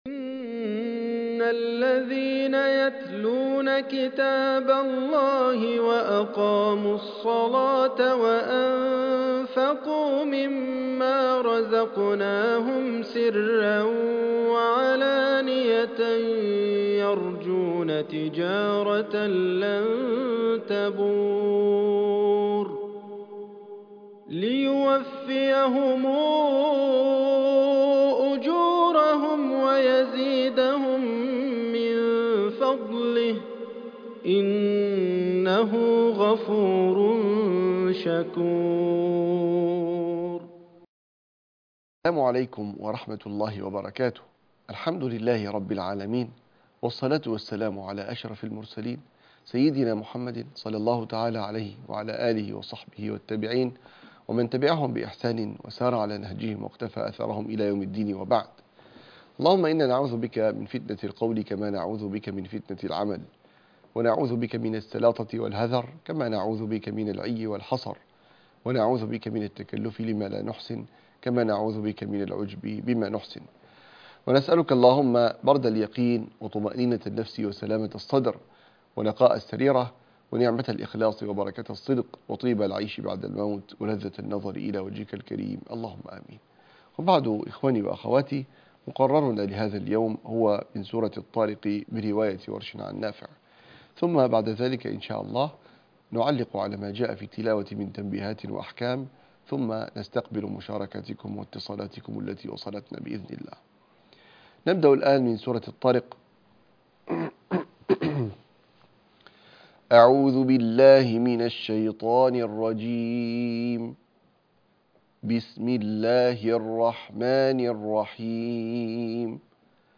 مقرر التلاوة سورة الطارق -الحلقة السابعة عشر -مقرأ ورش 2